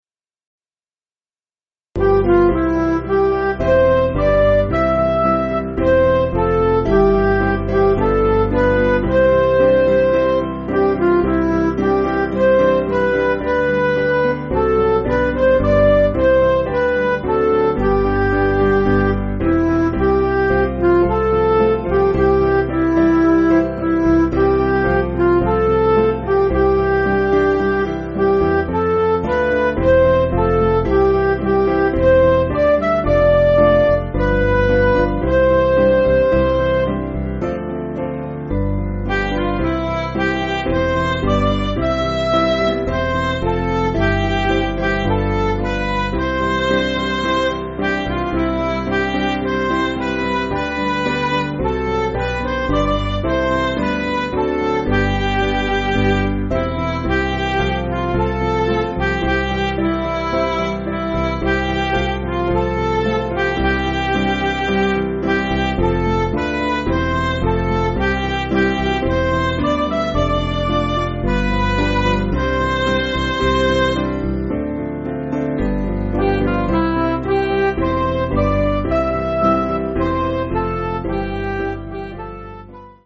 Piano & Instrumental